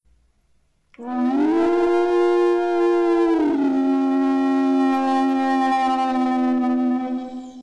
A warg howl for your sound board.
warg-howl.mp3